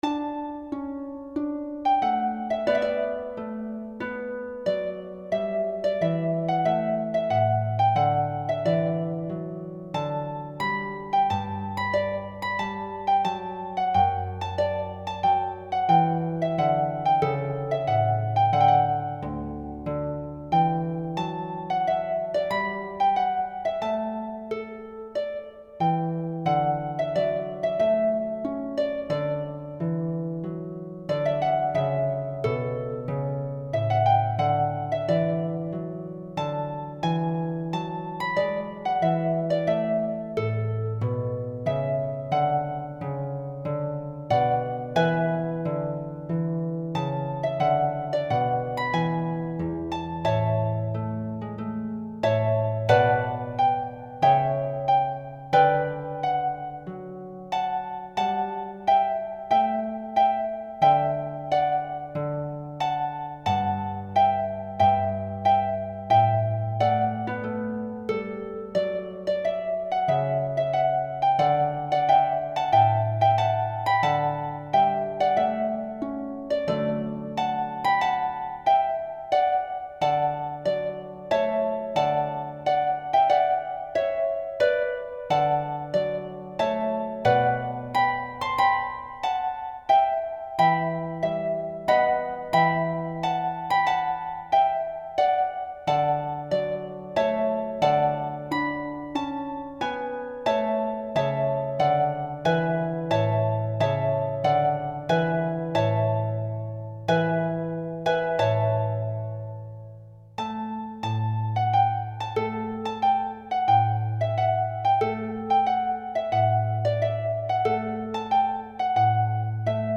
Voluntary (Stanley) (midi)